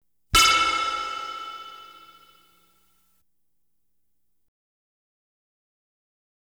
Light Beam Hit Sound Effect
Download a high-quality light beam hit sound effect.
light-beam-hit-6.wav